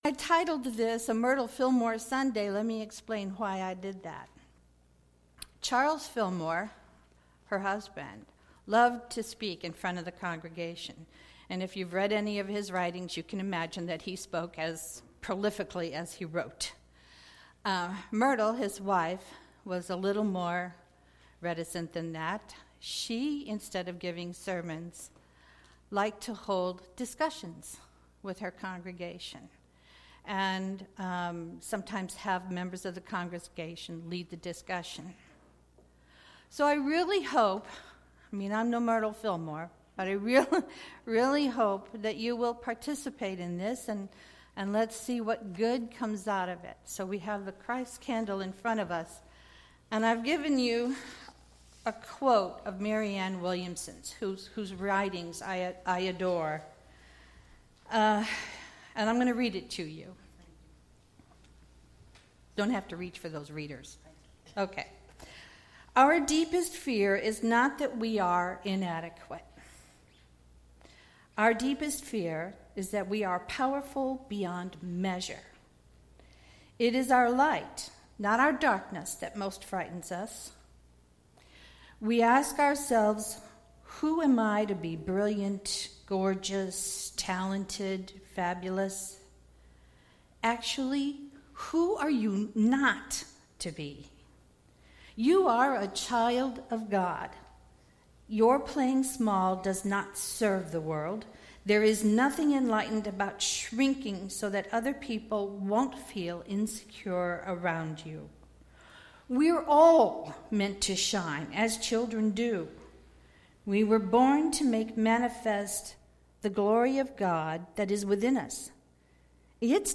Series: Sermons 2016